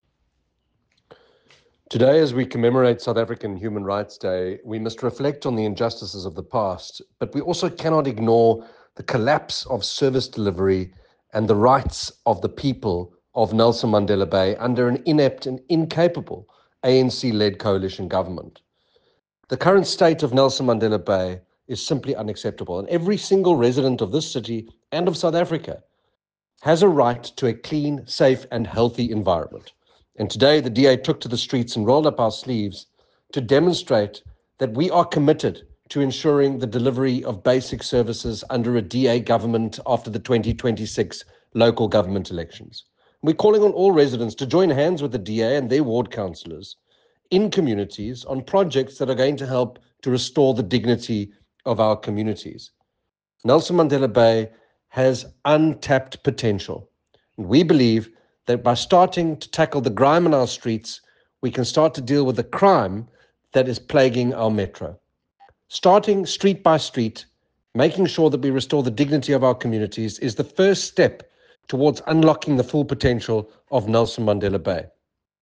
soundbite delivered by Andrew Whitfield MP, DA EC Provincial Leader, at the Gqeberha City Hall after an extensive clean-up campaign across Nelson Mandela Bay.